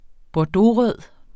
bordeauxrød adjektiv Bøjning -t, -e Udtale [ bɒˈdoˌʁœðˀ ] Betydninger dyb rød farve med en brunlig tone, som rødvin Synonymer bordeaux vinrød Bordeauxrøde roser pynter på bordene i Den Gamle Kro.